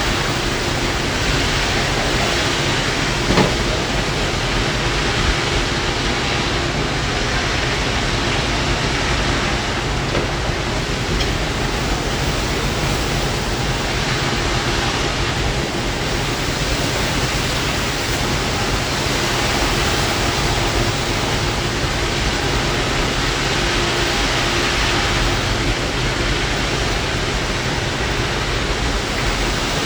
AMB Outside 1
Ambience I recorded on 2022-11-11 19:23:09
amb_outdoor1_loop.ogg